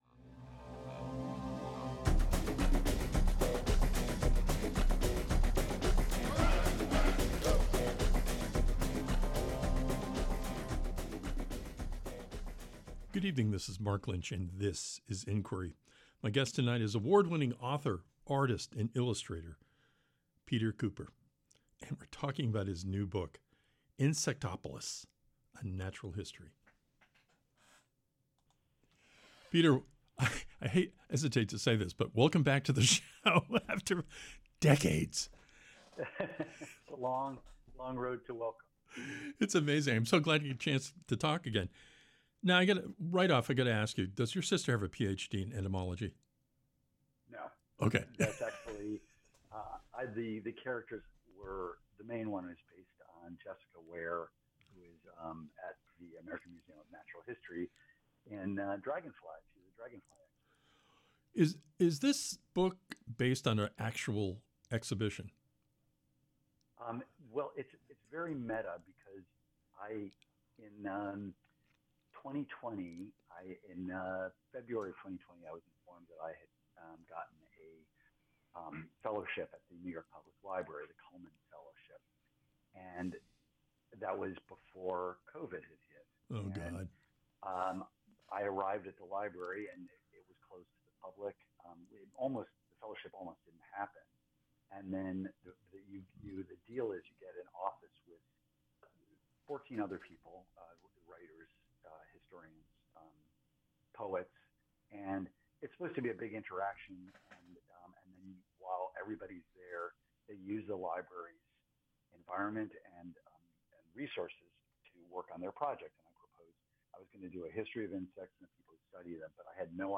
On this episode of Inquiry, we talk with author, artist, and illustrator PETER KUPER about his new stunning graphic history of insects, entomologists, and human history, INSECTOPOLIS: A NATURAL HISTORY.